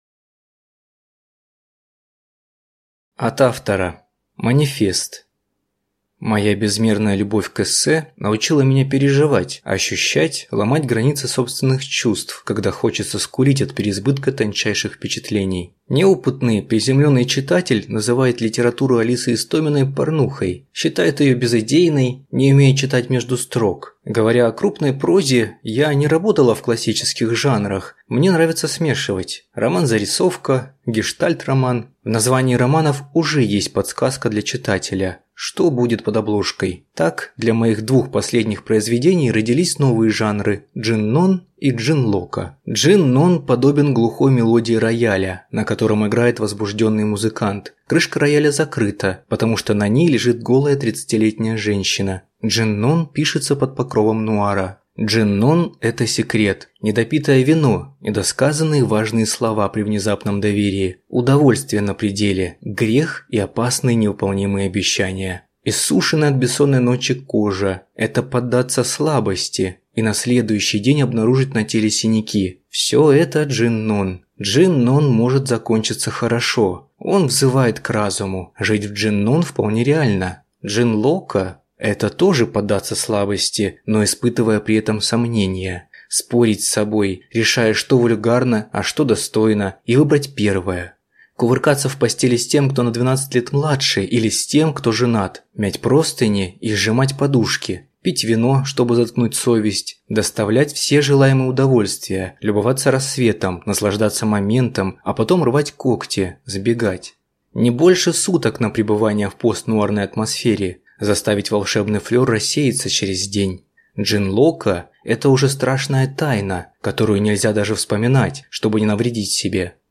Аудиокнига Три комнаты. Сборник малой прозы | Библиотека аудиокниг